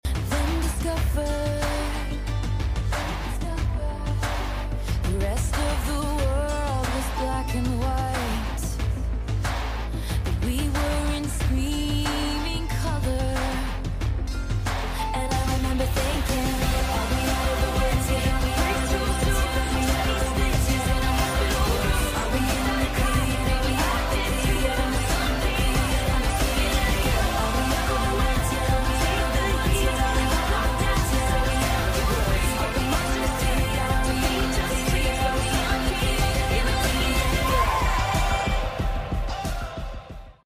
overlapped edit audio